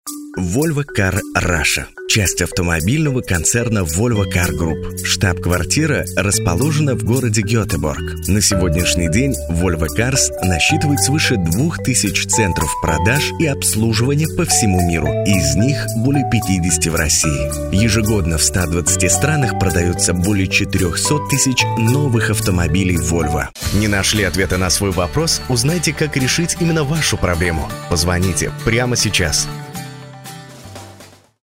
RODE NT USB, без подготовленной студии
Демо-запись №1 Скачать